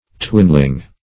Search Result for " twinling" : The Collaborative International Dictionary of English v.0.48: Twinling \Twin"ling\, n. [Twin + 1st -ling.] A young or little twin, especially a twin lamb.